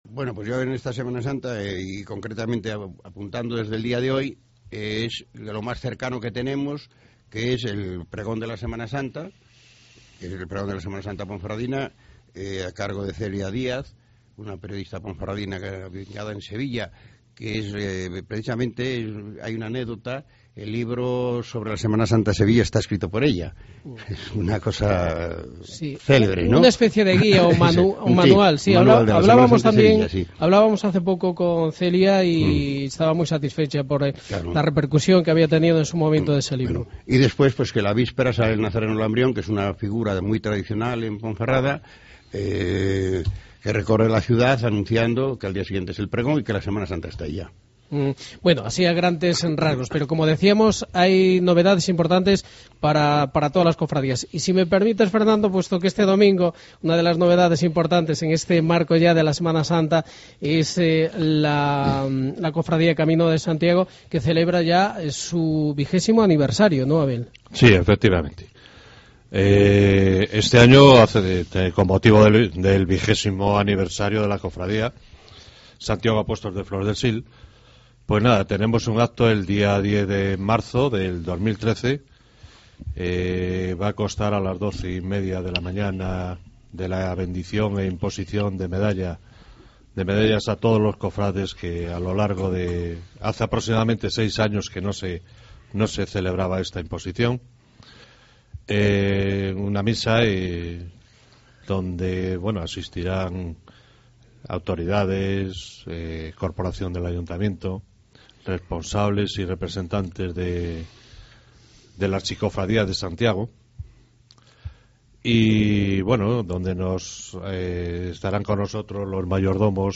Entrevista representantes Semana Santa Ponferrada 2013